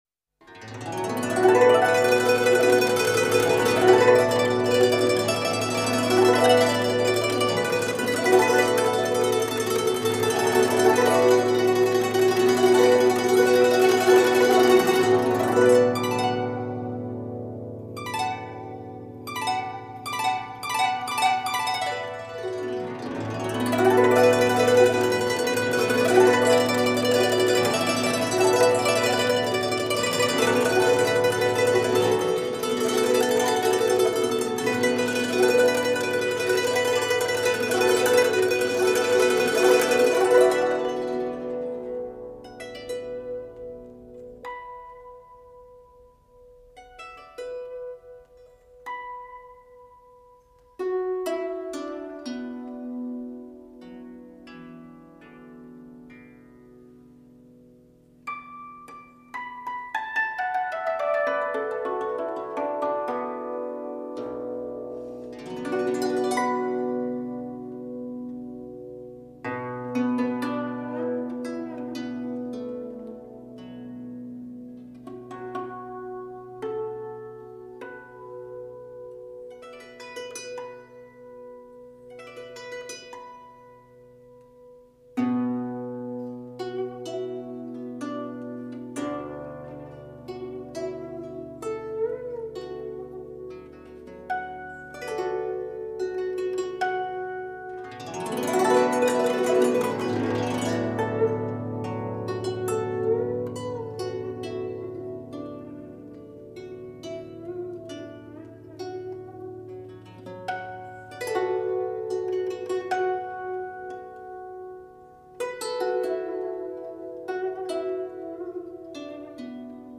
用雅潔坦蕩的音樂内涵，歌頌了竹子的高尚氣節,音色純美而富有表現力。